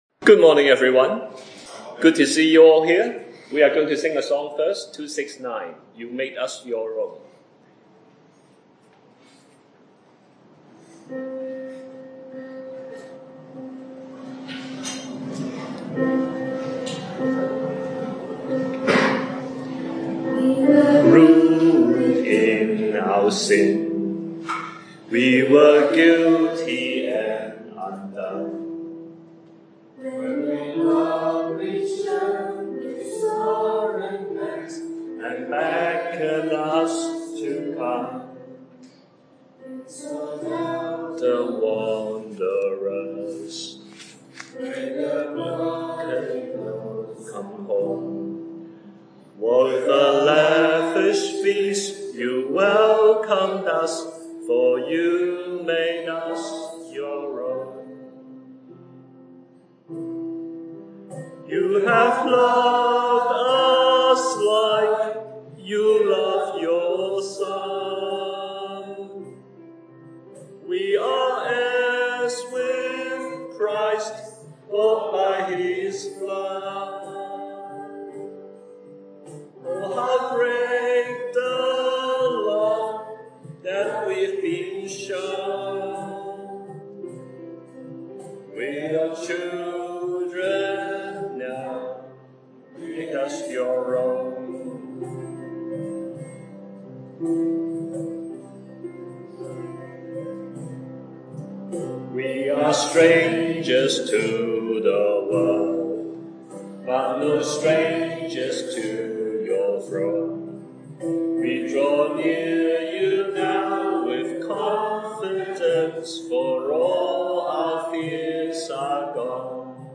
A gospel talk about how the Lord Jesus came and sought out a poor rich man named Zacchaeus and transformed him into a rich poor man.